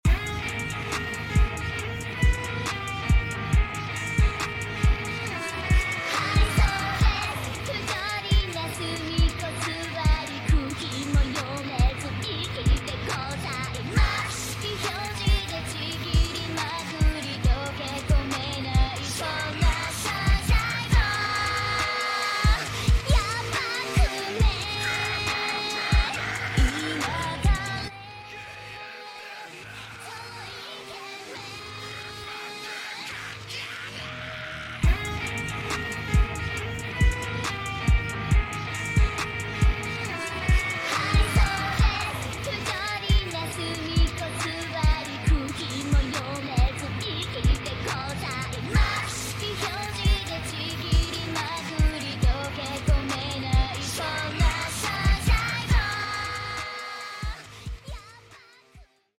Trap Beat Remix